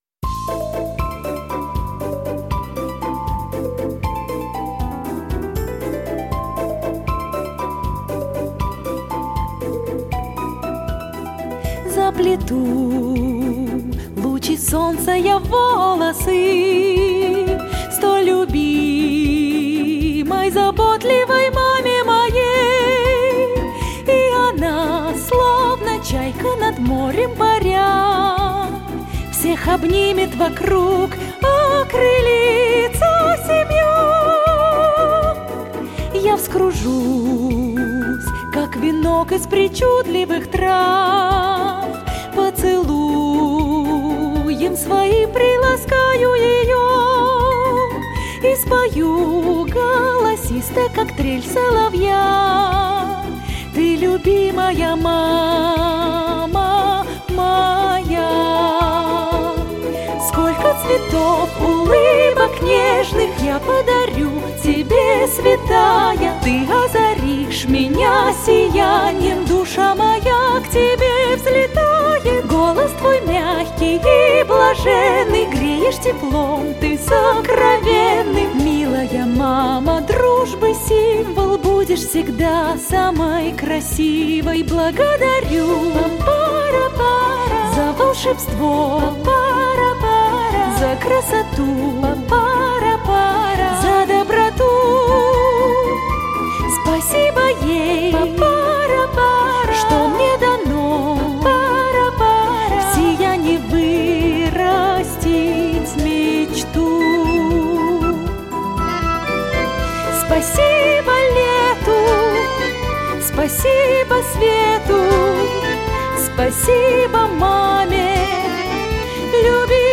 • Жанр: Детские песни